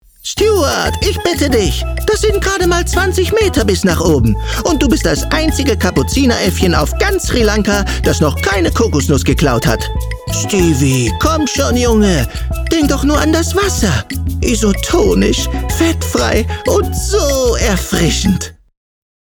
plakativ, markant, sehr variabel
Ruhrgebiet
Children's Voice (Kinderstimme)